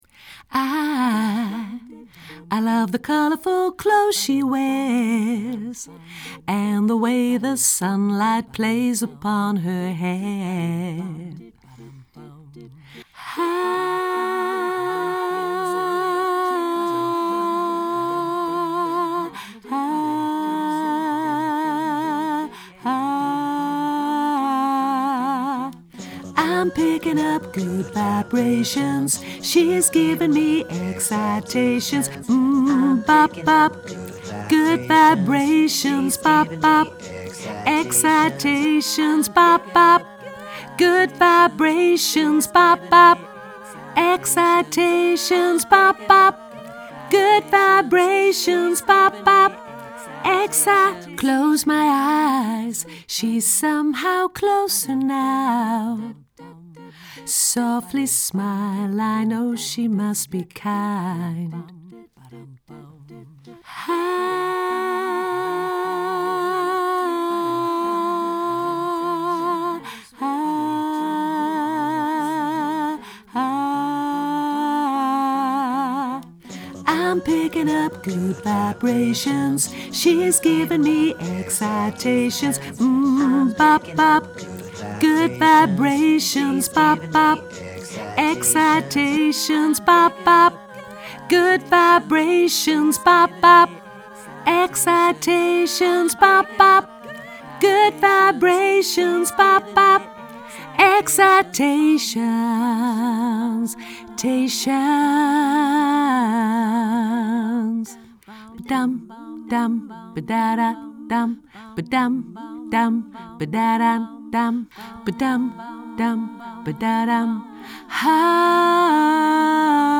alt laag